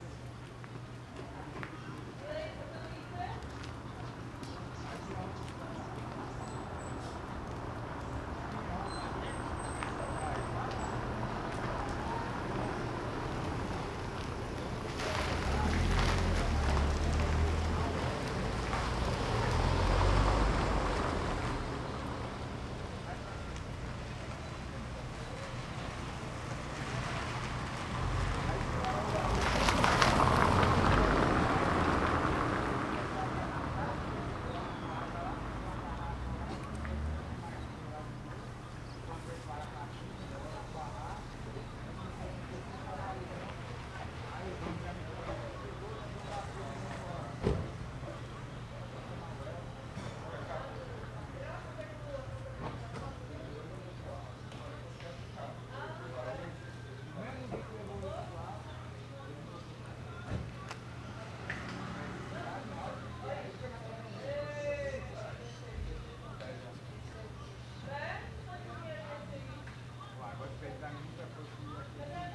CSC-04-353-OL- Ruas da Vila de São Jorge de manha.wav